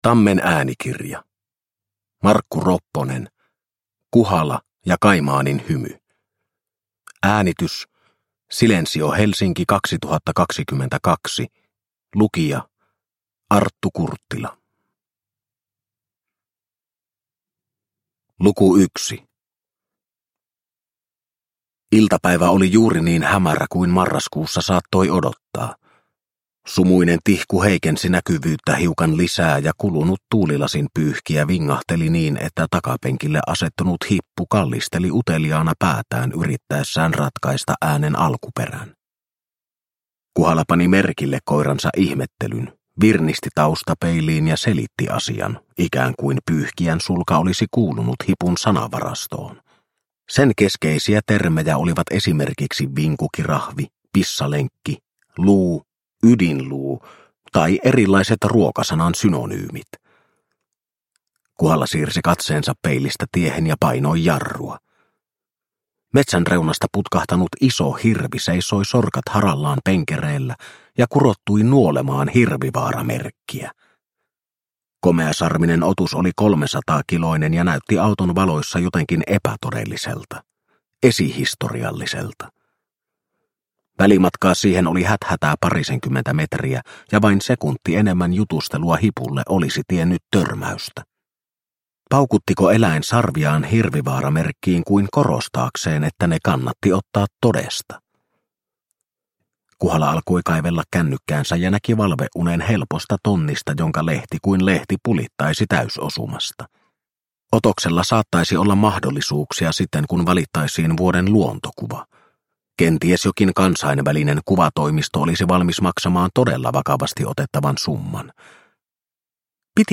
Kuhala ja kaimaanin hymy – Ljudbok – Laddas ner